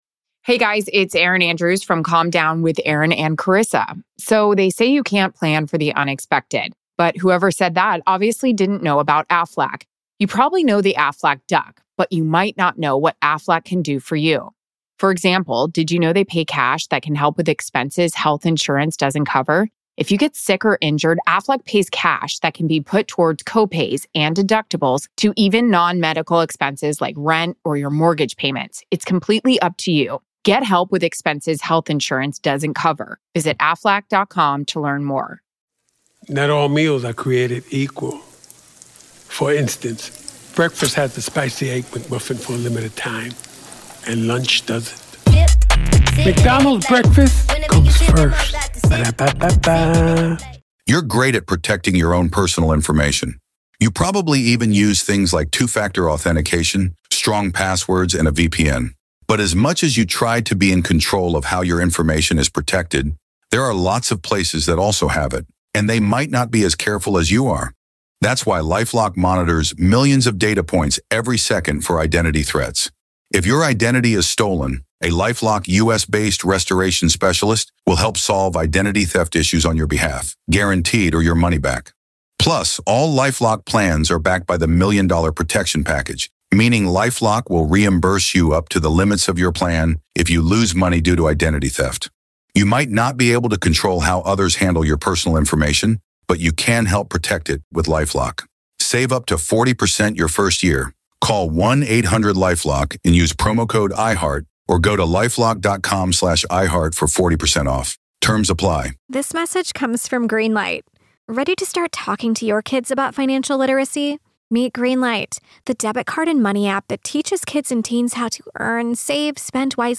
From the increasing trend of vehicle-ramming incidents to New Orleans' shaky track record with major events, this conversation pulls no punches in scrutinizing what needs to change—and fast.